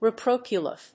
• / rɪpróʊtʃf(ə)l(米国英語)
reproachful.mp3